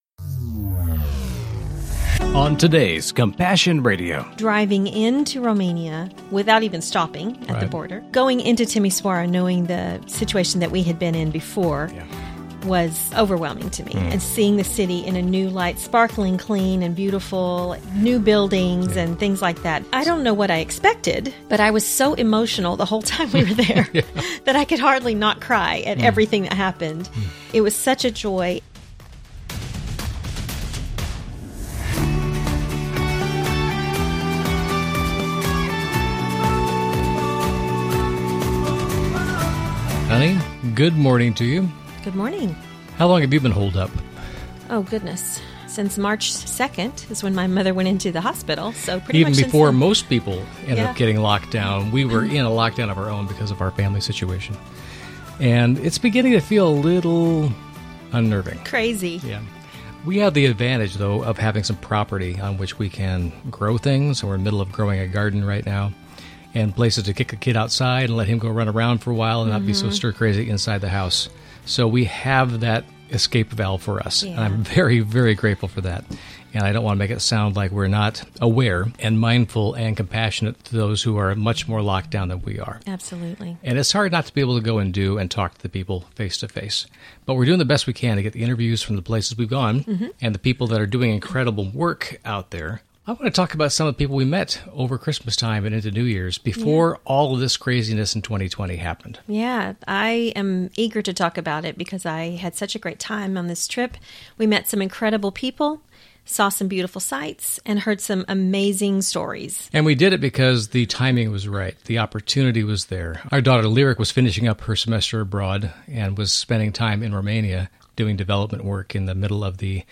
Genre: Christian News Teaching & Talk.